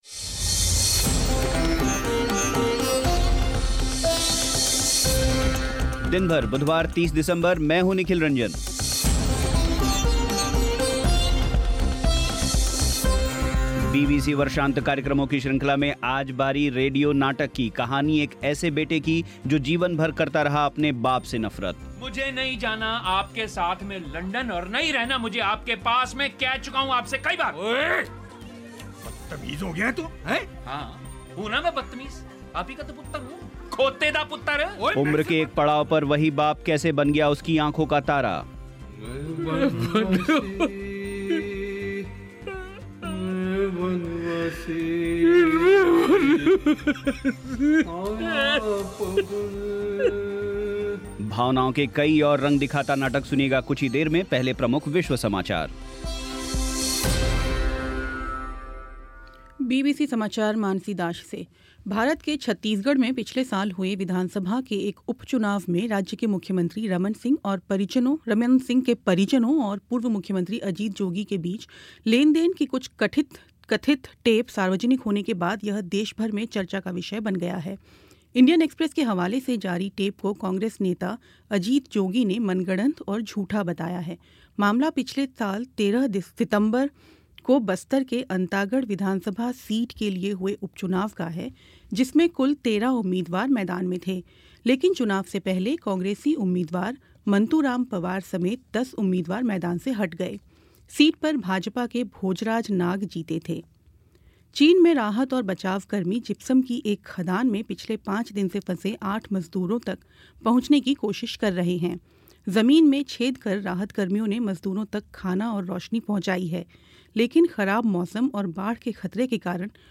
बीबीसी वर्षांत कार्यक्रमों की श्रृंखला में आज बारी रेडियो नाटक बंधक की....कहानी एक ऐसे बेटे की जीवन भर करता रहा अपने बाप से नफ़रत....कहानी एक बाप की जो सात समंदर पार जा कर भी मुश्किलों के पार ना जा सकाभावनाओं के कई और रंगों से भरा नाटक